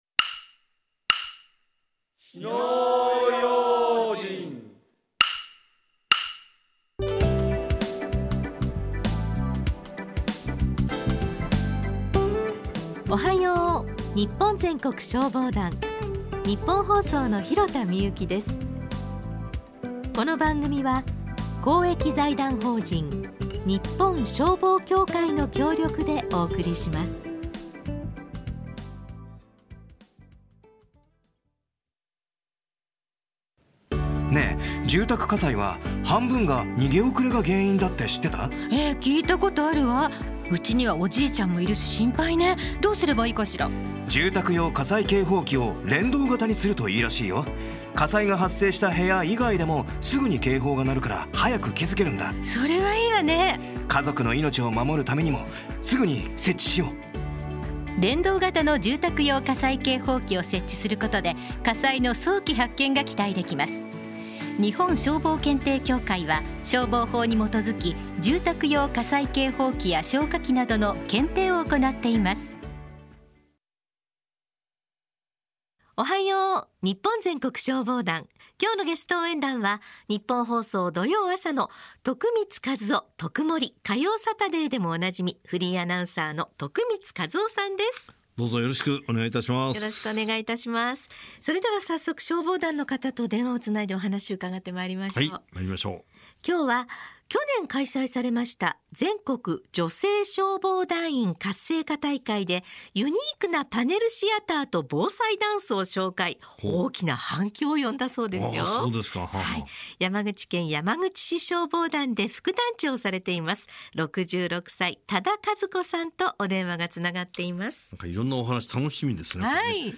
ラジオ放送 - ＴＯＰ - 日本消防協会
徳光さんの問いかけが優しく、とても話しやすかったです。